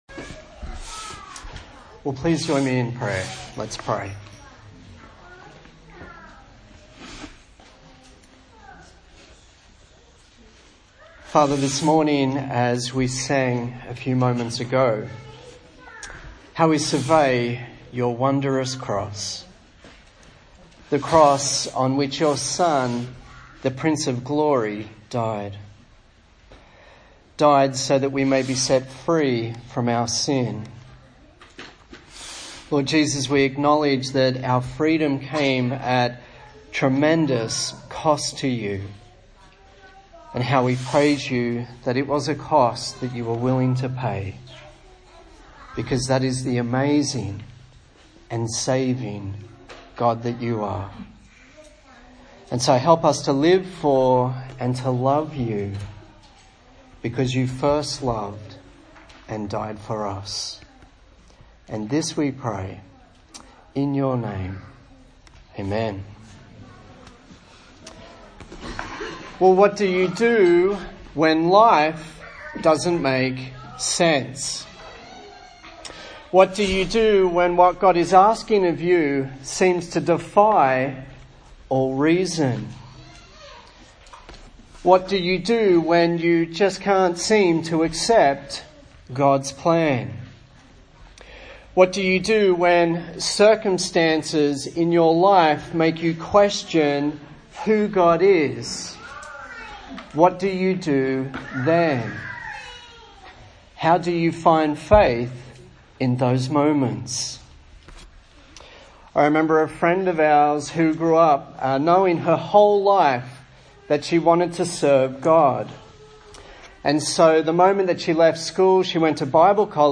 Genesis Passage: Genesis 22 Service Type: Good Friday